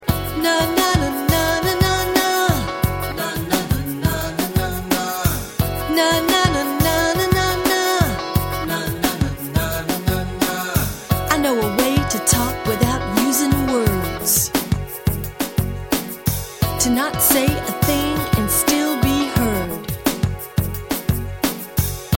A Multicultural Music Appreciation Song